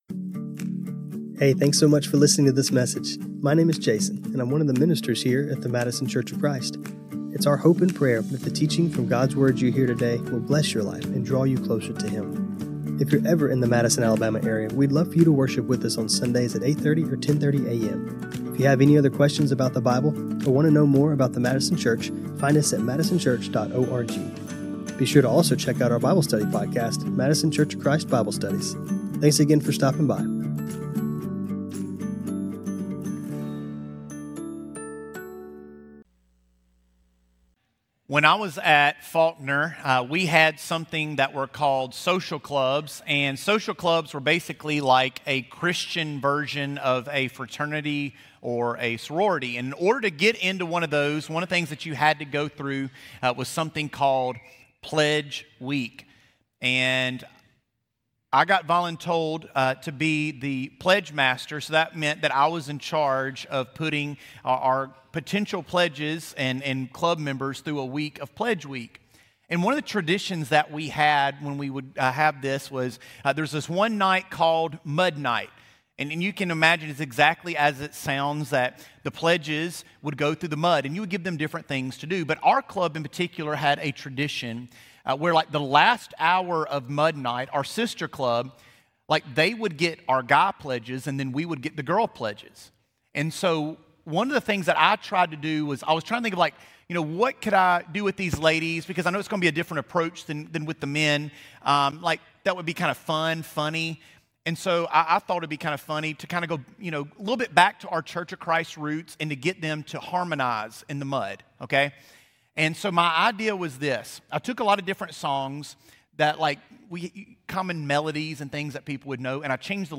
This lesson focuses on how Jeremiah's time in the cistern shows us that our own periods of being stuck in mud pits aren't just low points with no greater purpose, and how changing our viewpoint to those being pitstops makes for positive change. Jeremiah 38:1-13 This sermon was recorded on Jan 18, 2026.